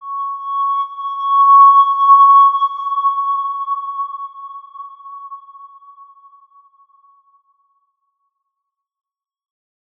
X_Windwistle-C#5-pp.wav